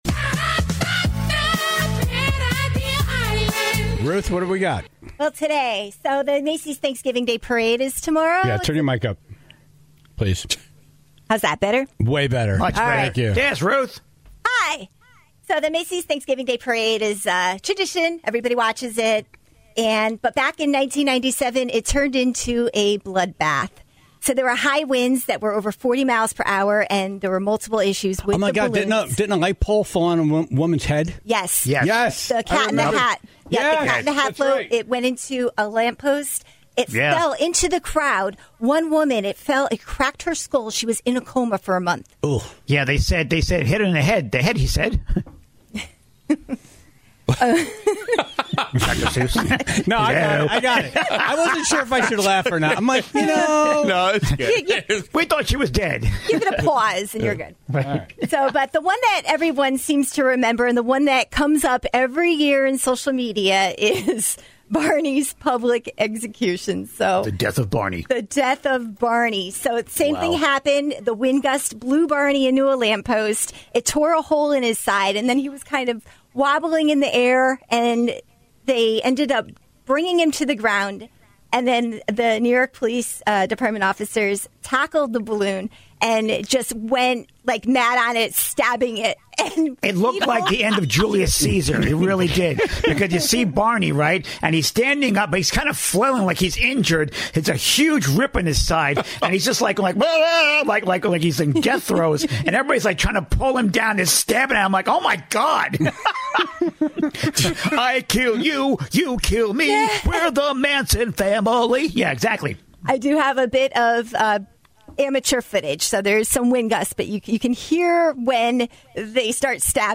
One message did not fit the montage, Senator Blumenthal, who earned an entire segment on its own for the bizarre food choice and loud background conversation.